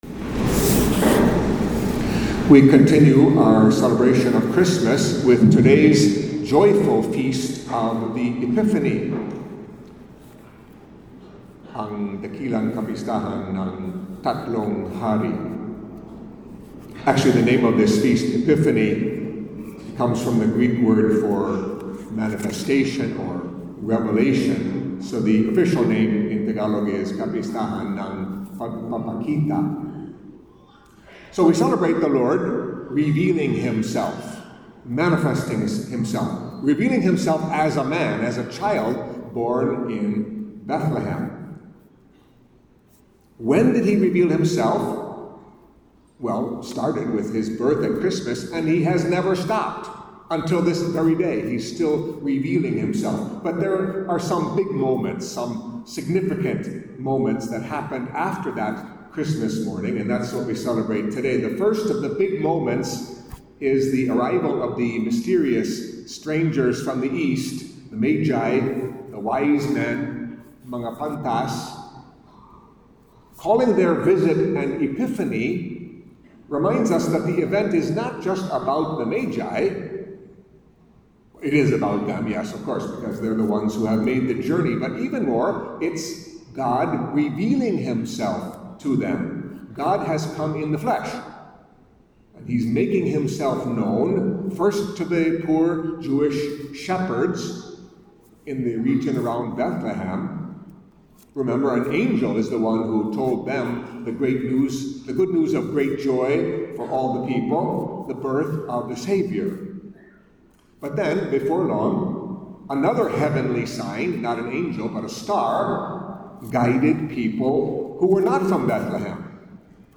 Catholic Mass homily for the Epiphany of the Lord